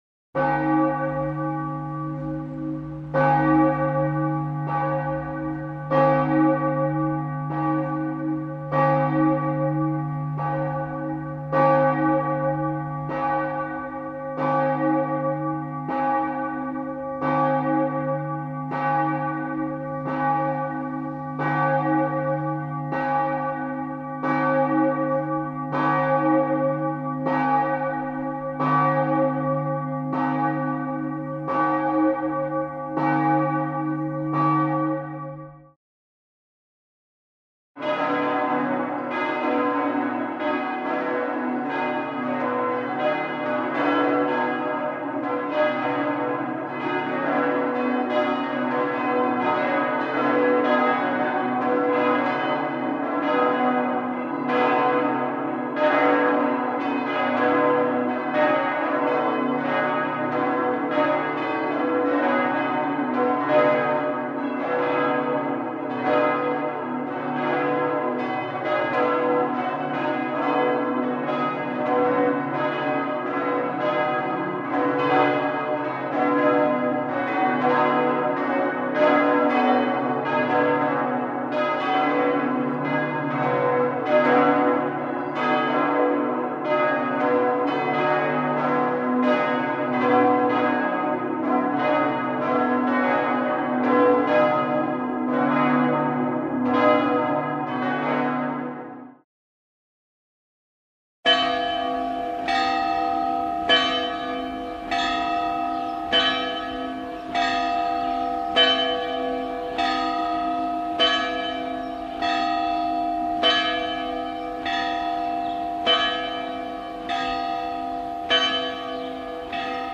SFX钟声音效下载